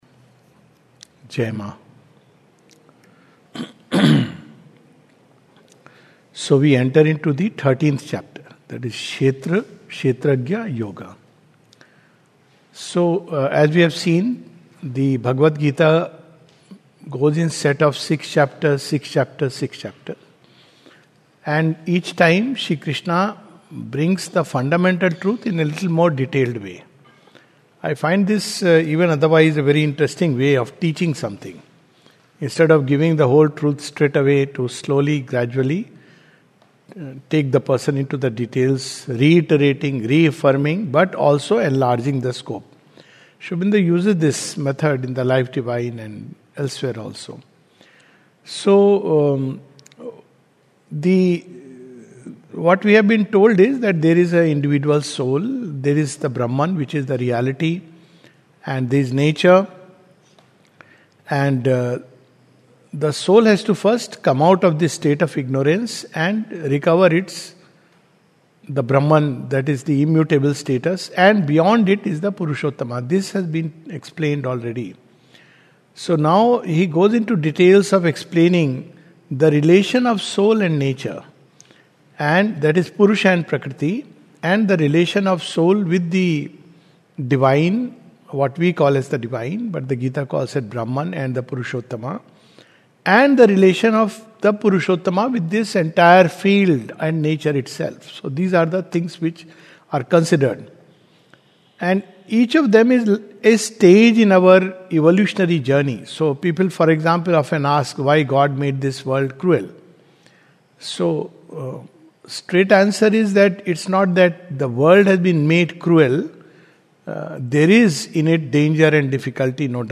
This is a summary of Chapter 13 of the Second Series of "Essays on the Gita" by Sri Aurobindo. Talk
recorded on October 8, 2025 at the Savitri Bhavan, Auroville.